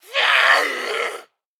attack_8.ogg